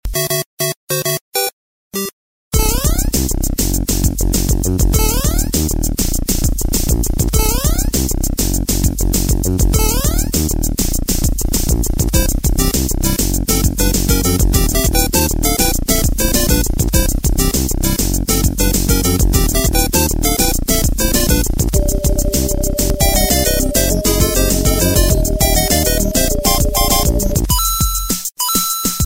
زنگ معروف نوستالژی خیلی از گوشی ها